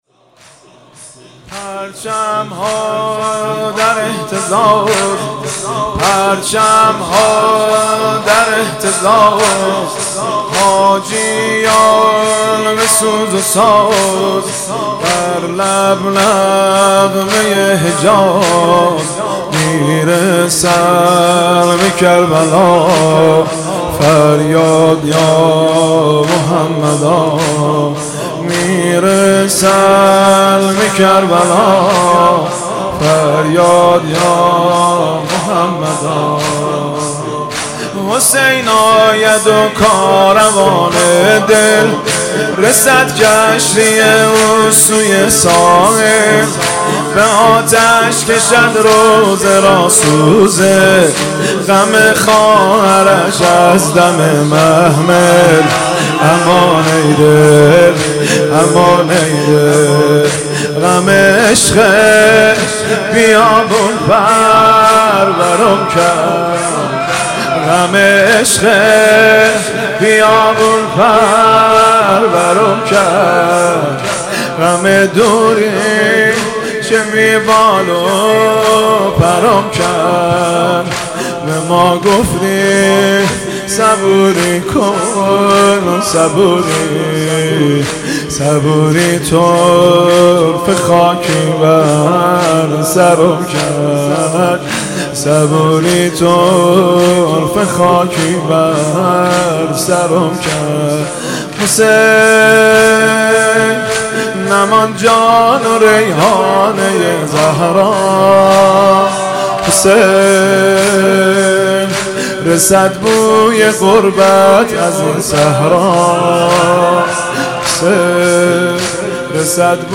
محرم1402 شب دوم